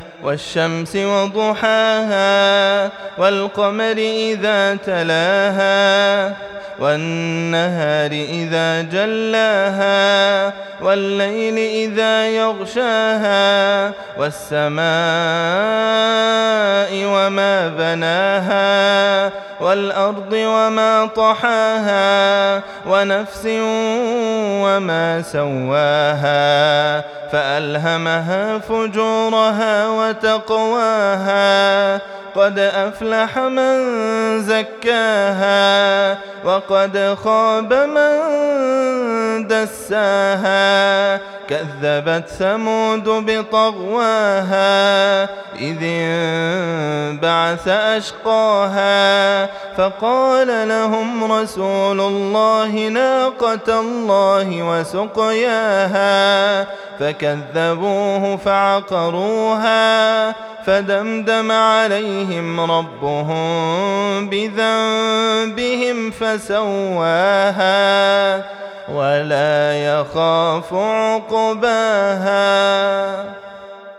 تلاوة هادئة تريح النفس والقلب لمن يبحث عن راحة البال ❤ سورة الشمس رمضان ١٤٤٣ ❤